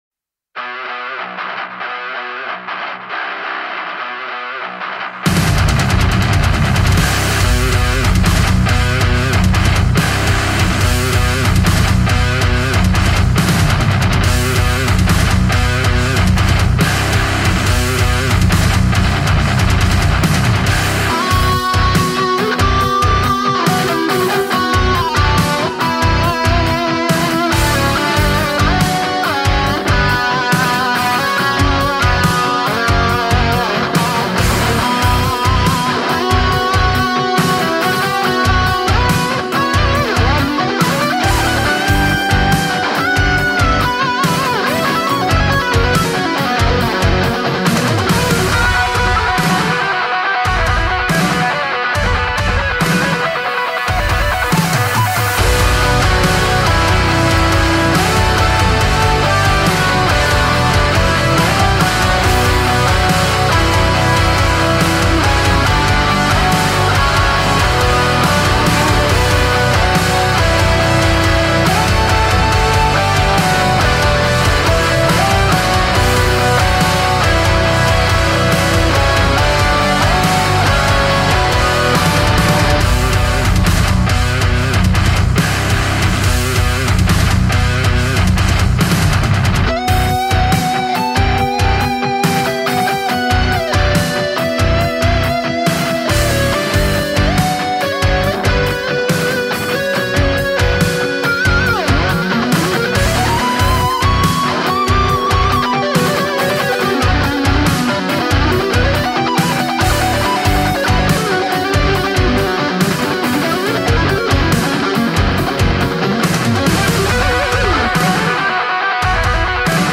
"Не тот" звук после записи. Не понимаю, в чём проблема...
Так, ну вот что получилось у меня, проект 44100, 32 с плавающей, гейн на линии на 12, на мейн выходе так же на 12, ничего не ровнял, нормализовал и так далее, все как есть, только трек разделил на два канала при импорте для удобства Вложения Оригинал.mp3 Оригинал.mp3 9,2 MB Петля карты.mp3 Петля карты.mp3 9,2 MB